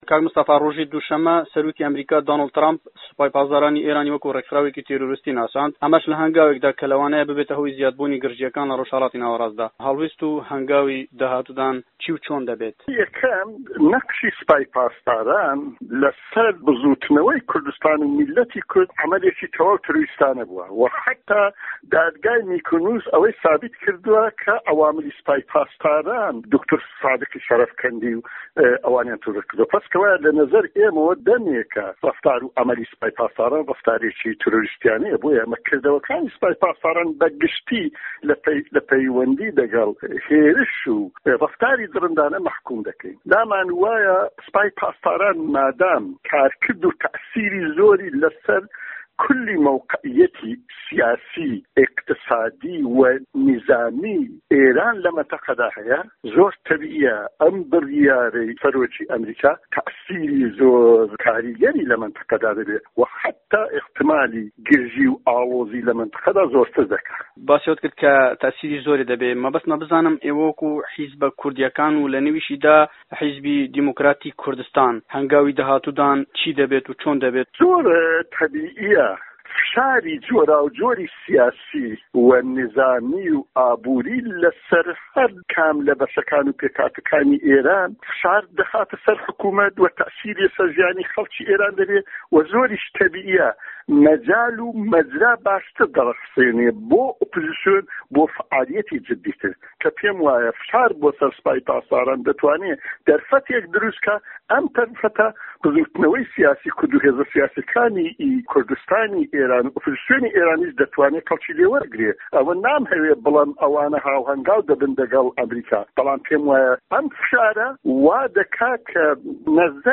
دەقی وتووێژەکەی مستەفای مەولودی